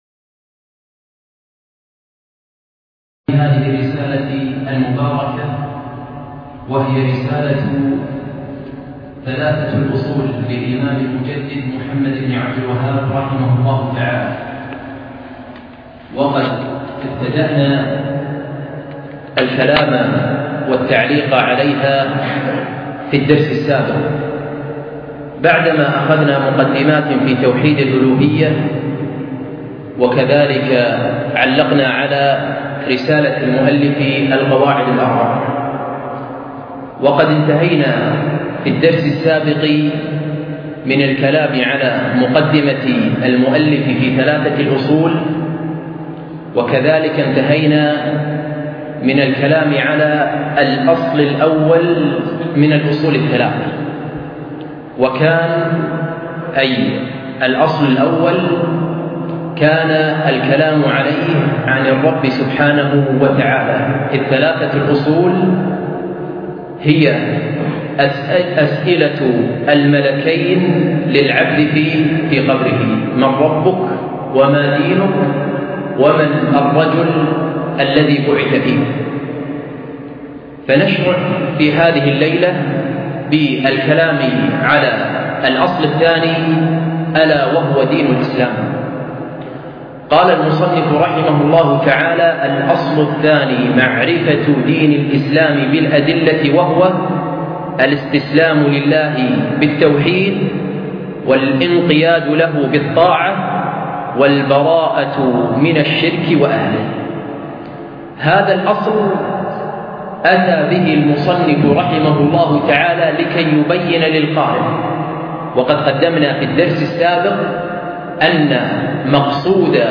أقيم الدرس في مسجد زين العابدين بمنطقة سعدالعبدالله منقول من قناة مشروع الدين الخالص